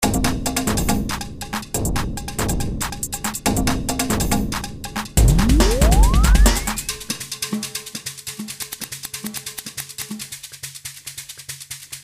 This song has really neat percussion loops in it.